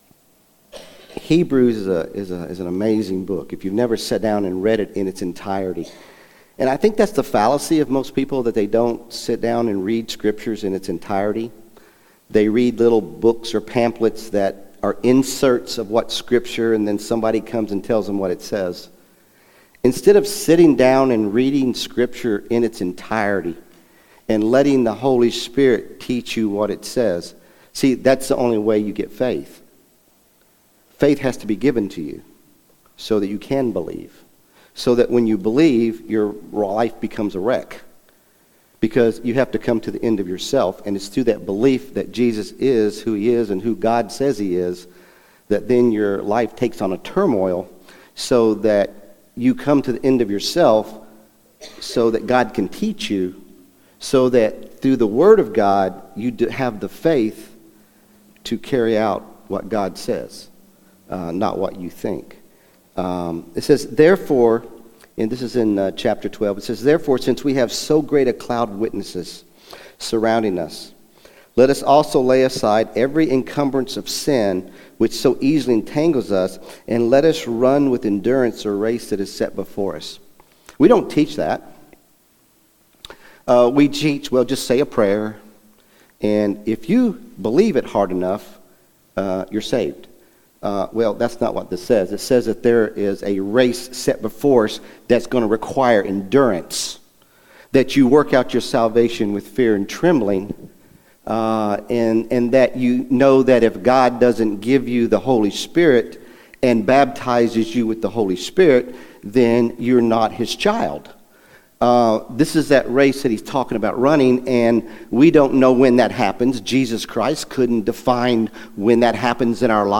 Sermon April 20, 2025 | South Elkhorn Baptist Church